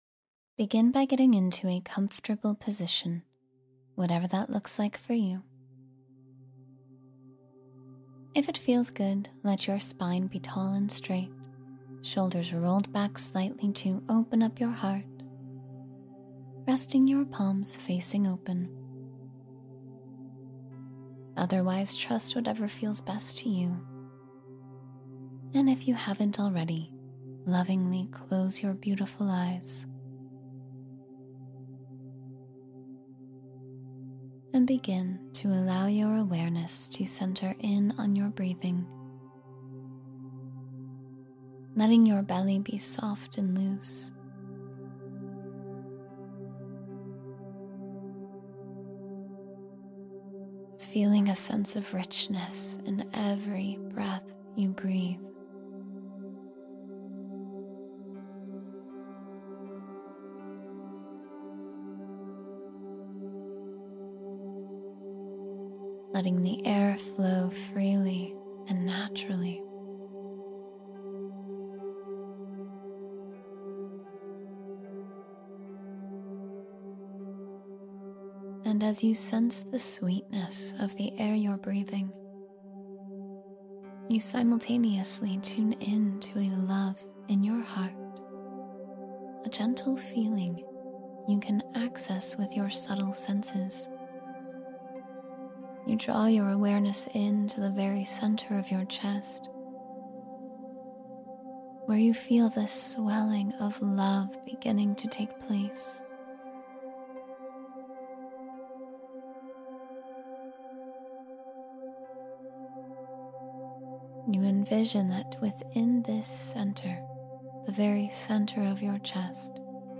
20 Minutes to Inner Wisdom and Positivity — Guided Meditation for Deep Insight